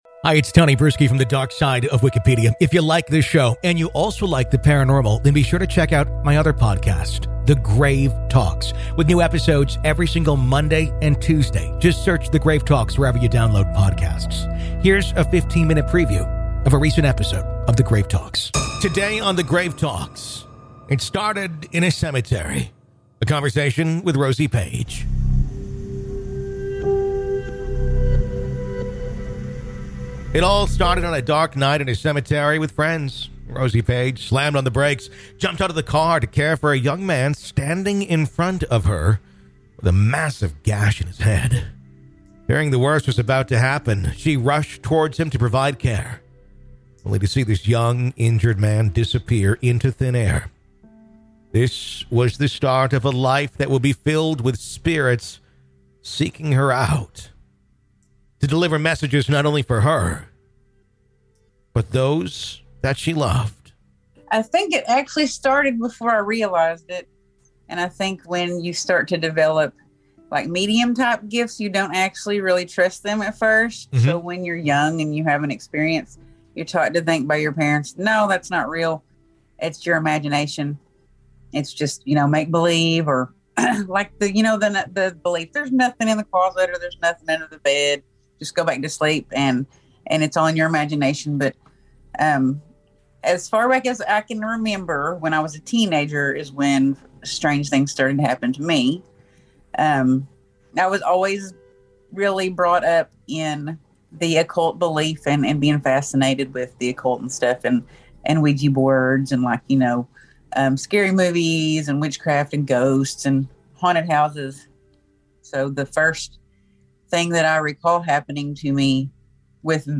True Crime Today | Daily True Crime News & Interviews / It Happened In A Grave Yard...